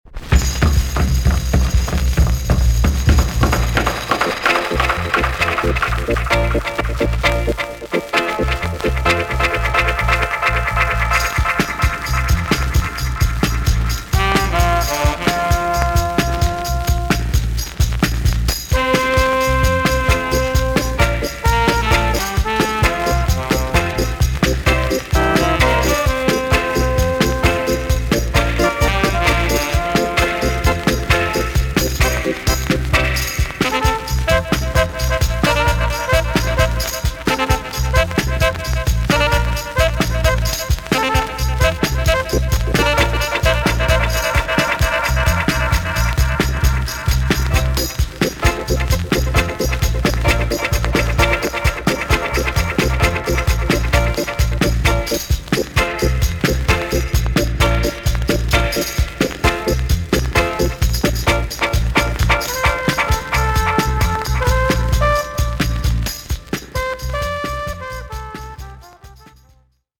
TOP >REGGAE & ROOTS
B.SIDE Version
VG ok 全体的にヒスノイズが入ります。